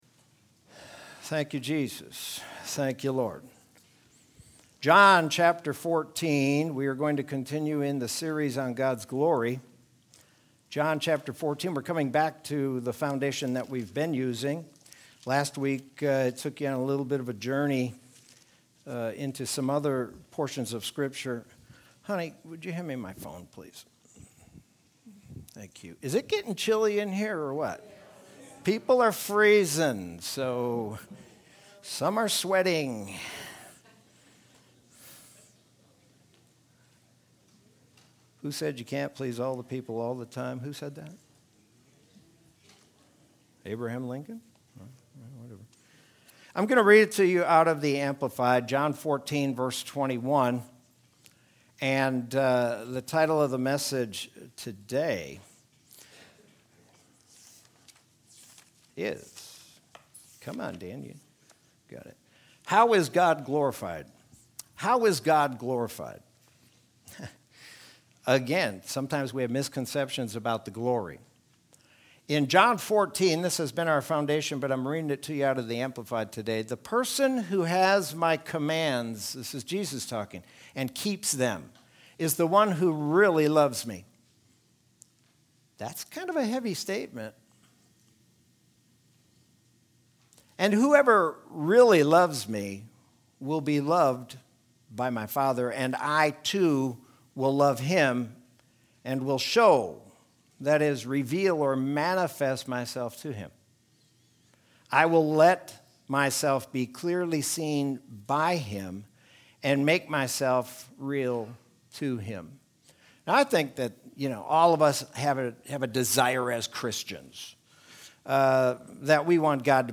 Sermon from Sunday, May 2nd, 2021.